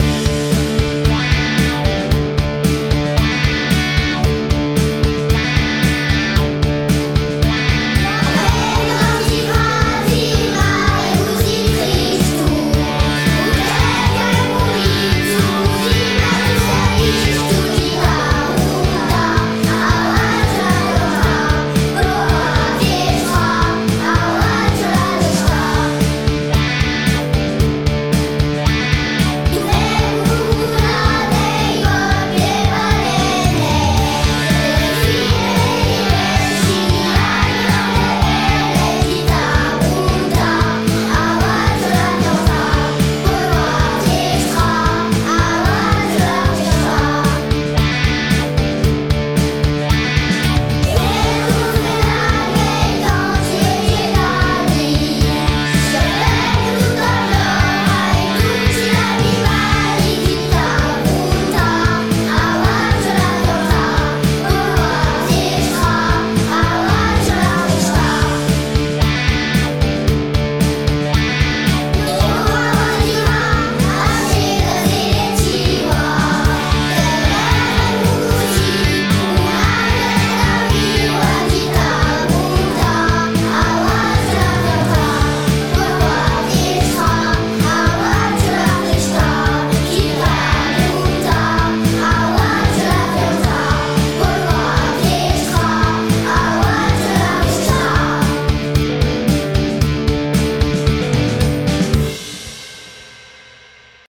PIANETTA VIVA versione rock